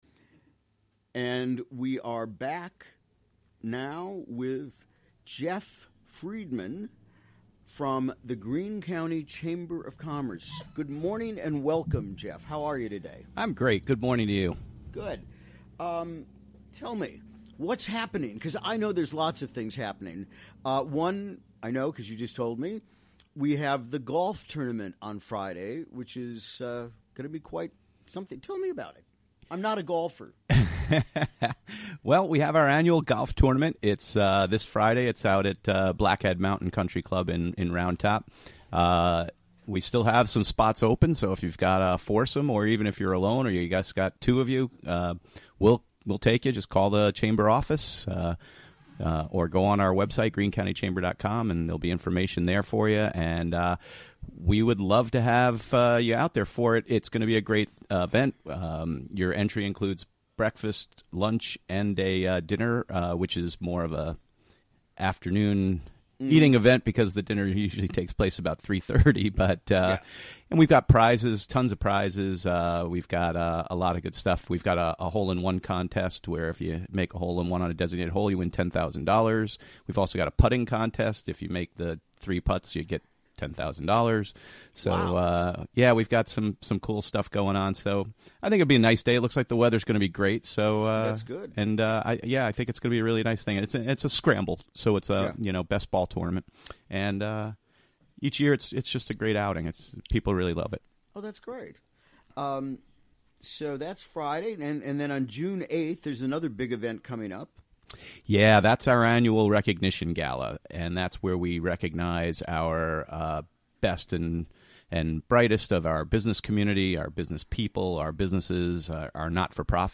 Recorded during the WGXC Morning Show on Wednesday, May 17.